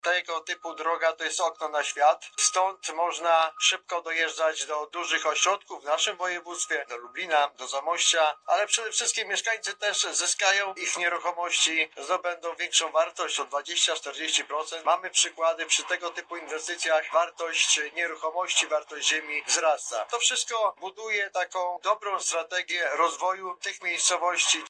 – mówi Wiesław Różyński, Poseł na Sejm RP.